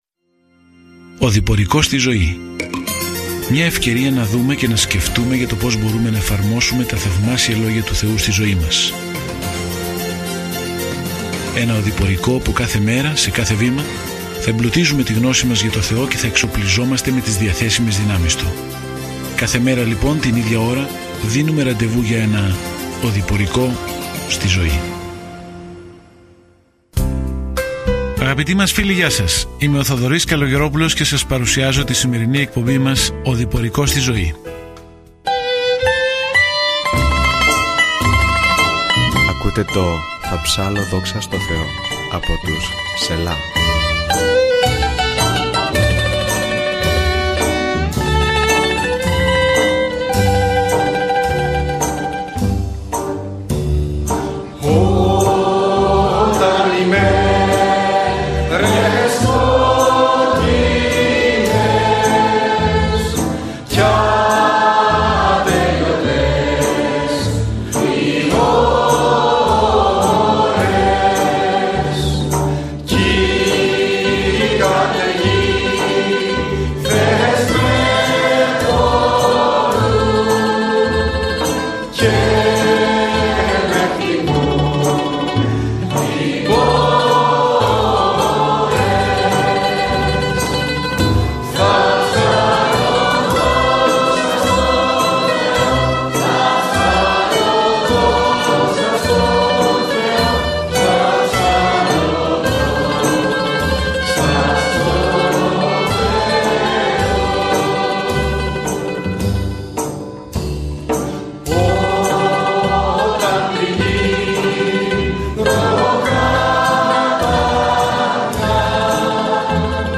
Καθημερινά ταξιδεύετε στον Ιώβ καθώς ακούτε την ηχητική μελέτη και διαβάζετε επιλεγμένους στίχους από τον λόγο του Θεού. More Θα θέλαμε να ευχαριστήσουμε το Thru the Bible για την παροχή αυτού του σχεδίου.